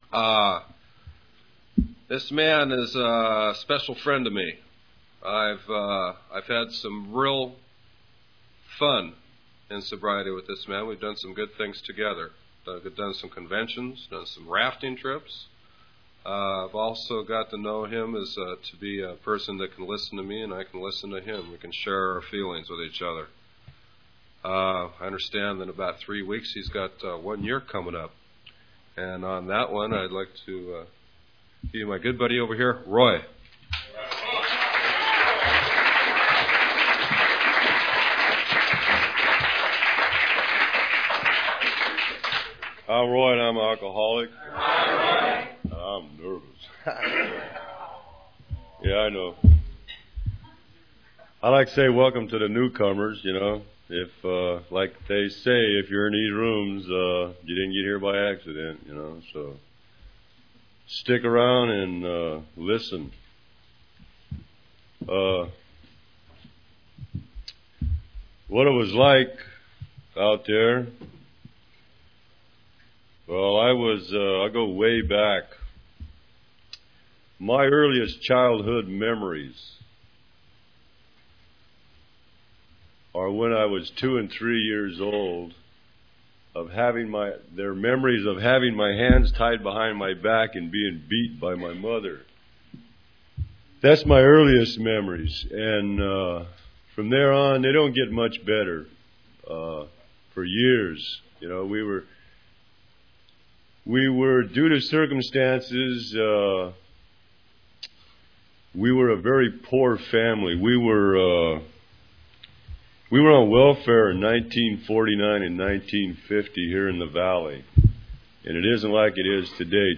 Speaker Tape